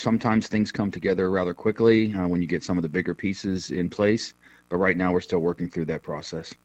State Representative Jim Struzzi said in an interview that it’s hard to say when a budget will be ready.